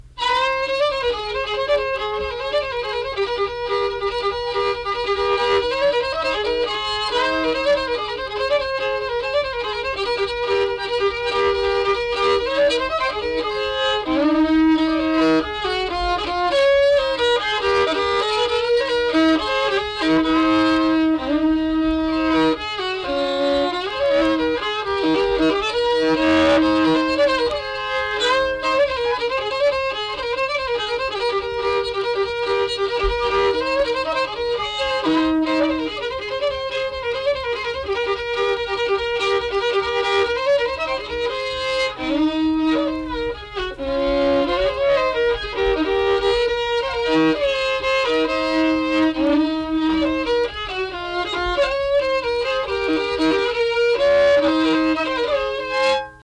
Unaccompanied fiddle music and accompanied (guitar) vocal music
Instrumental Music
Fiddle